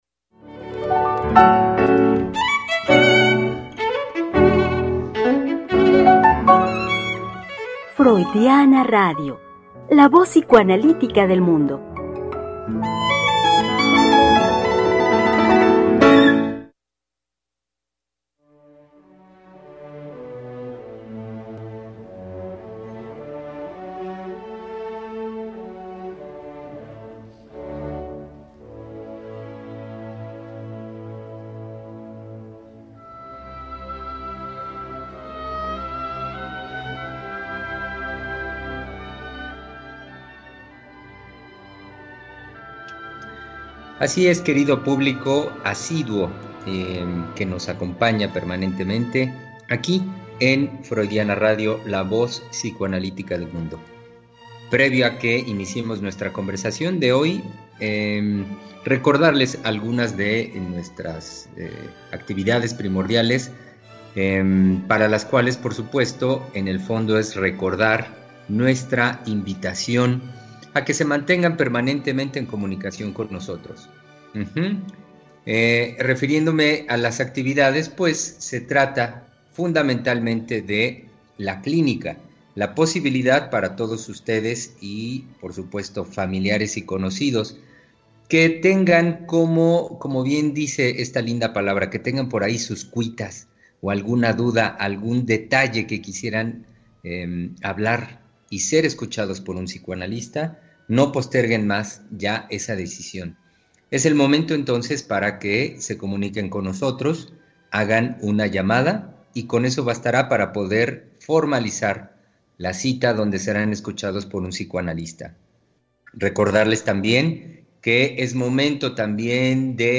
Conversación con los psicoanalistas